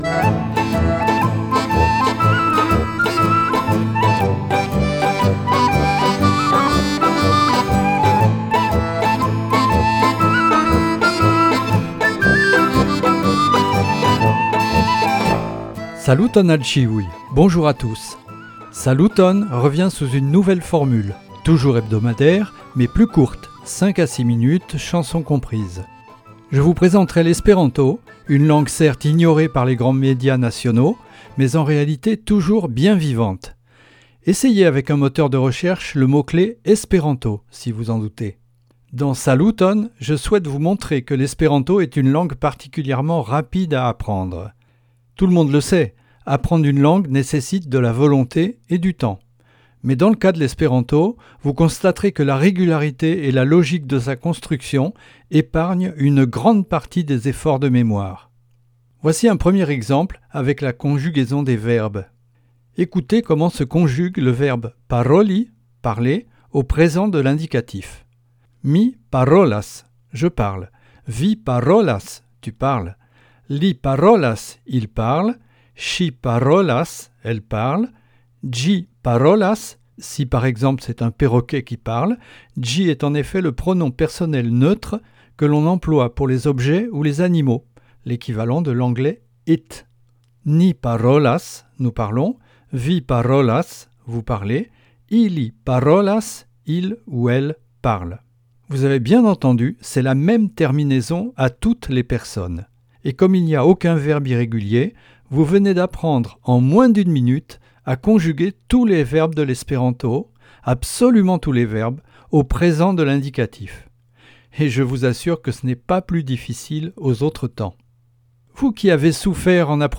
Atentu! Por respekti la aŭtorrajtojn, la kanzonoj de la ĉi-subaj dosieroj estas anstataŭigitaj per eltiraĵoj.
Vi nun povas disponi pli ol unu jaron da registritaj kursoj por komencantoj kompletigitaj per raportoj, intervjuoj, kanzoneltiraĵoj...